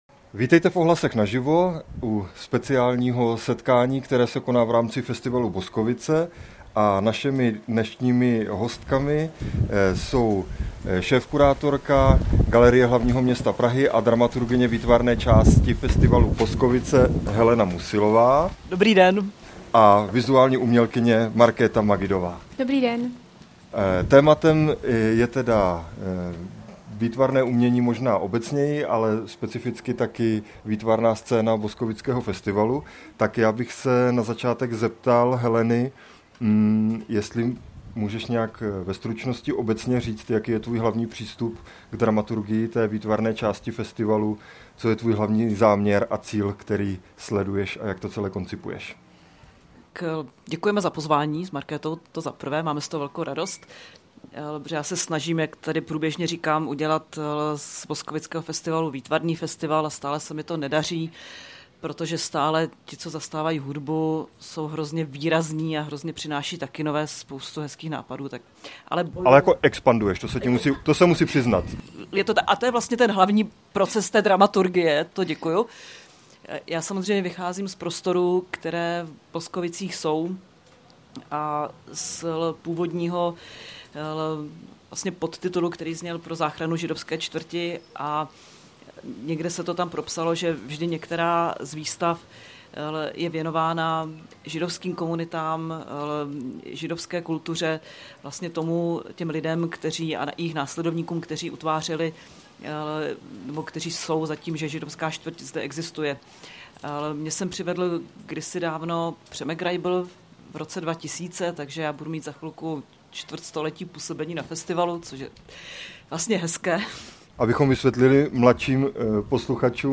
Živý rozhovor